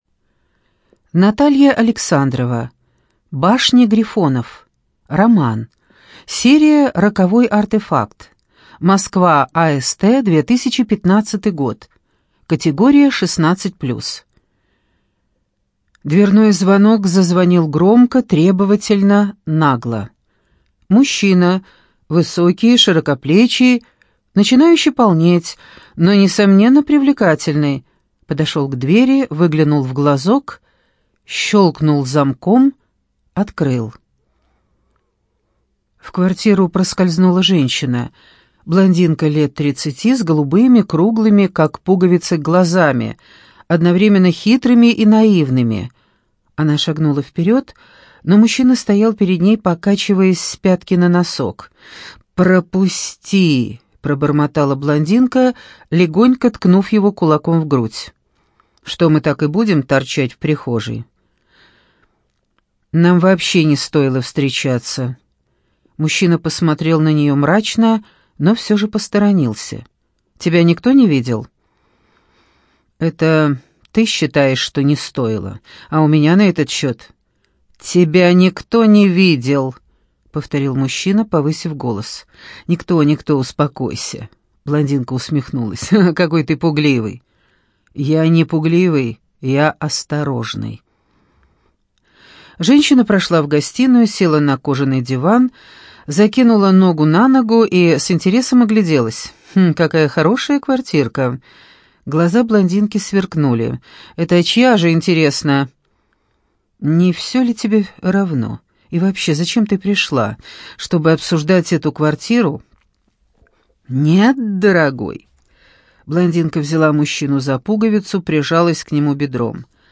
ЖанрДетективы и триллеры
Студия звукозаписиЛогосвос